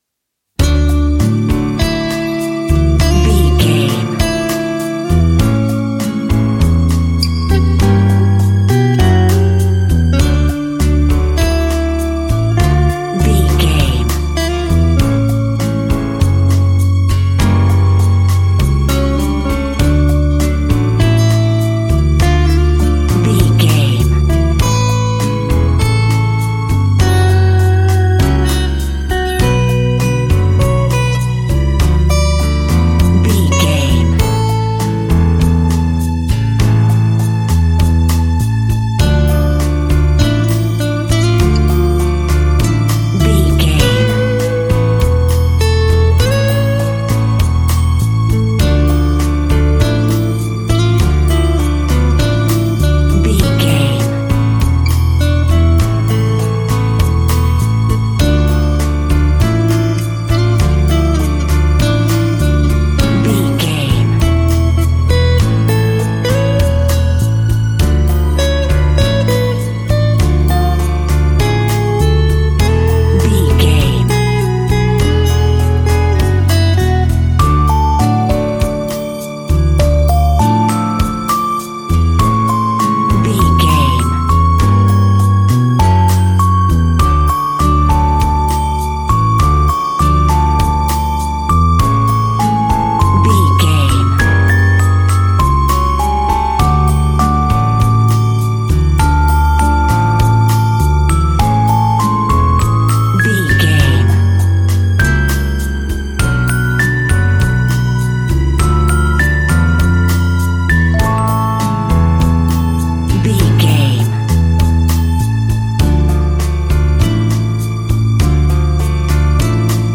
An exotic and colorful piece of Espanic and Latin music.
Ionian/Major
Slow
flamenco
romantic
maracas
percussion spanish guitar
latin guitar